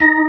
FST HMND D#4.wav